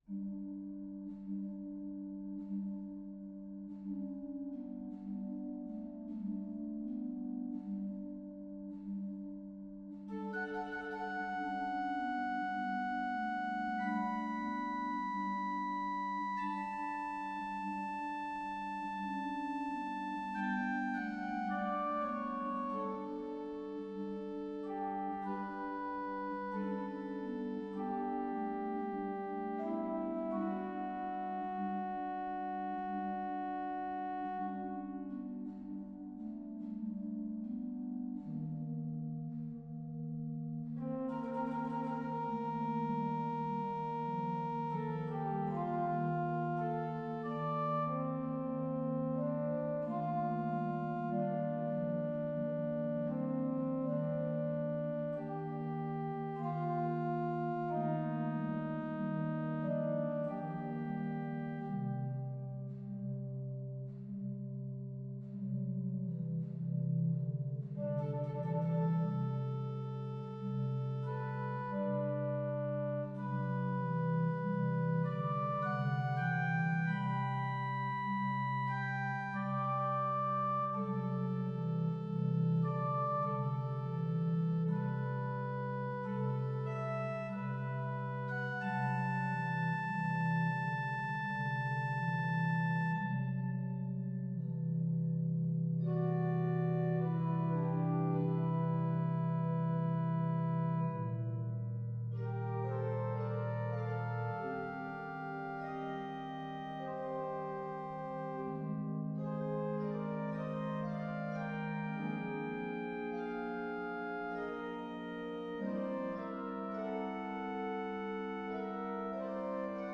It is a quiet, lyric meditation.